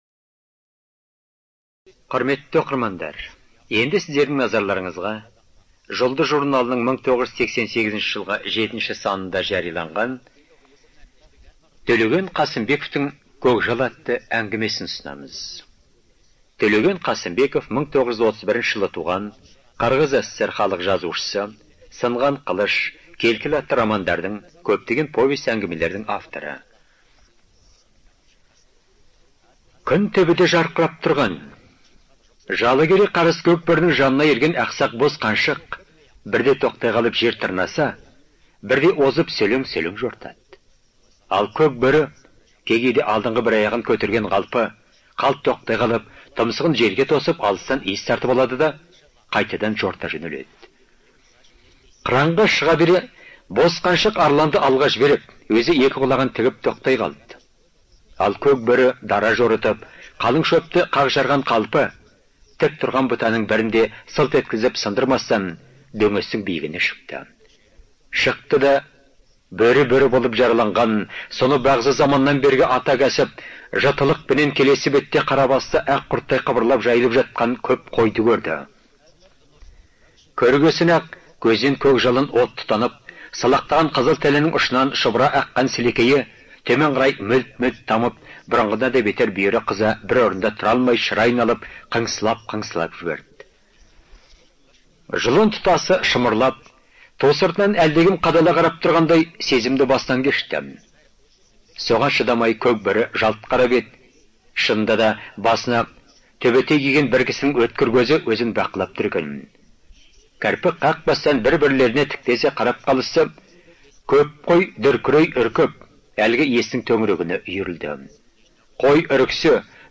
Студия звукозаписиКазахская республиканская библиотека для незрячих и слабовидящих граждан